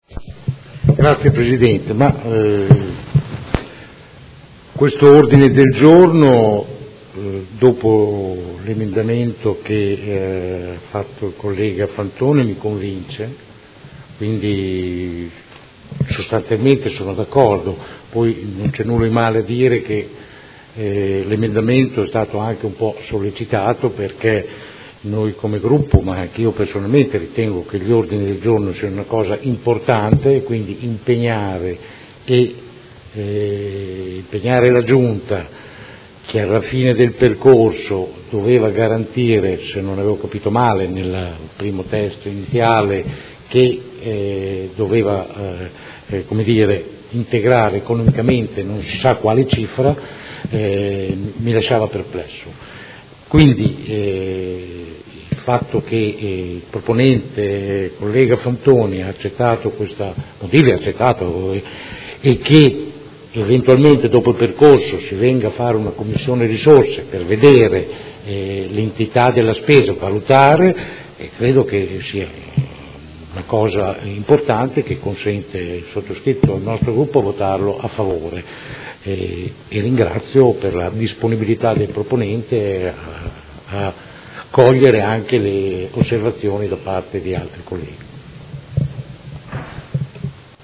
Francesco Rocco — Sito Audio Consiglio Comunale
Dibattito su Ordine del Giorno presentato dal Gruppo Movimento 5 Stelle avente per oggetto: Concorso di sculture su rotonde ed Emendamento P.G. n. 153482